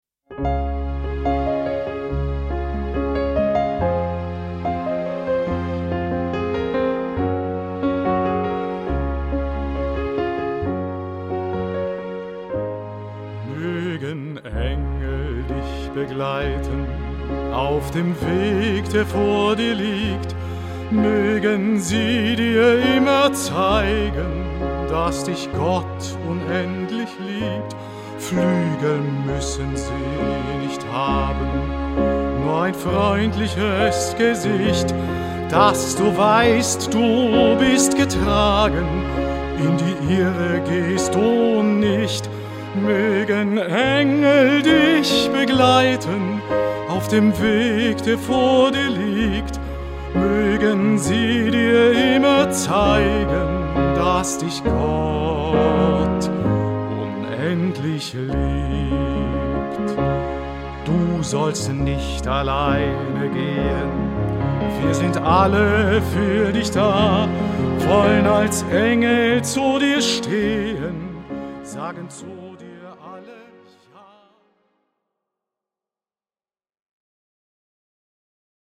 Live-Mitschnitte: